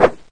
somen_woosh.ogg